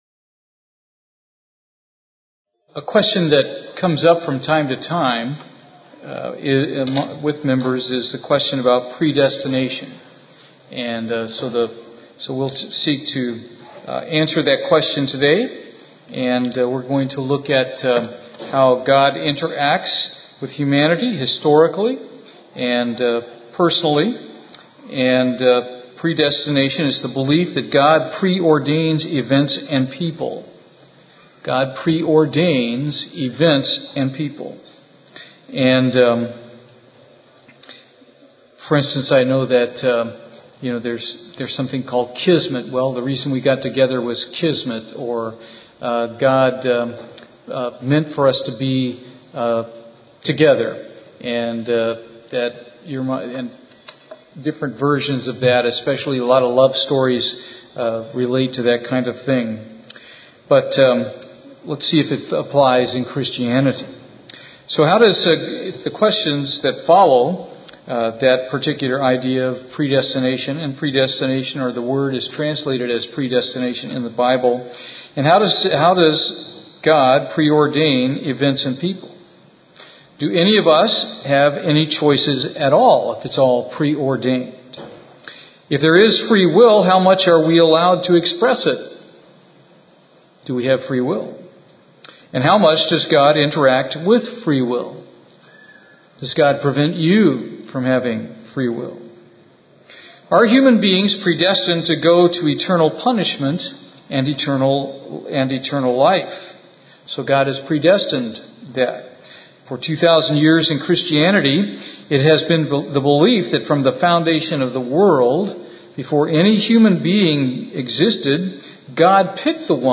Is this really what the Bible means when it uses the term predestination? this is the first of a to part Bible study on this subject.